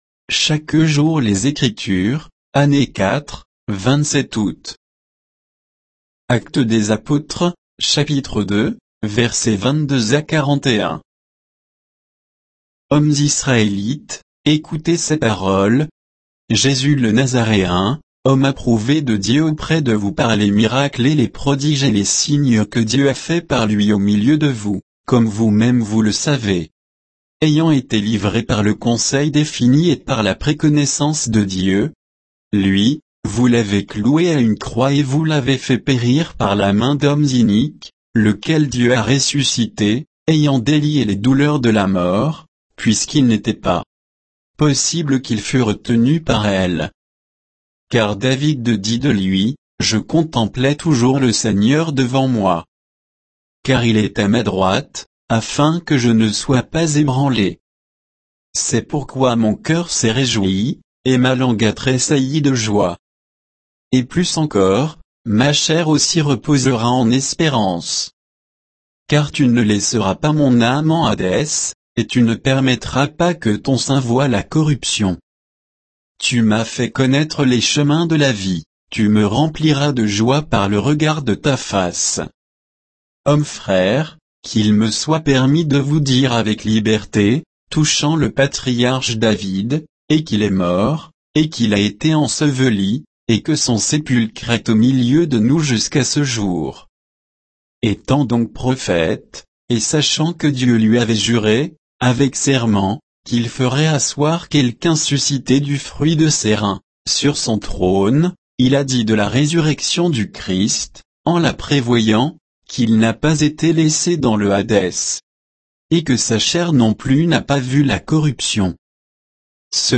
Méditation quoditienne de Chaque jour les Écritures sur Actes 2